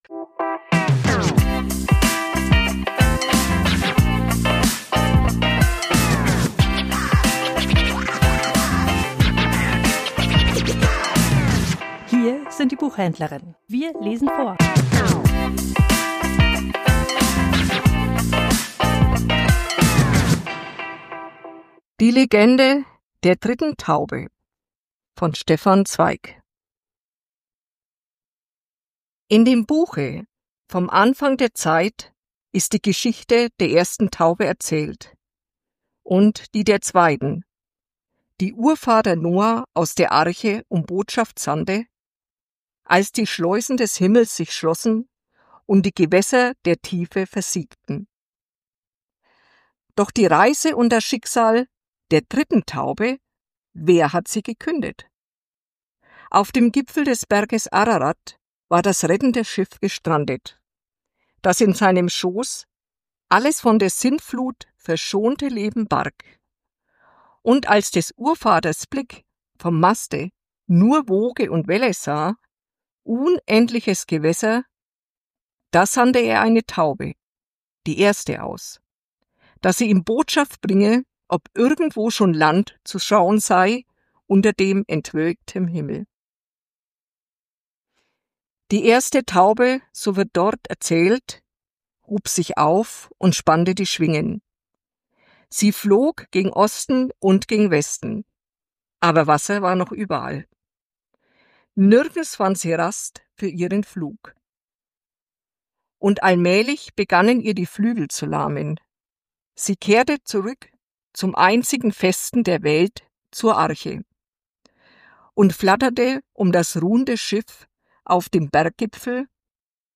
Vorgelesen: Die Legende der dritten Taube ~ Die Buchhändlerinnen Podcast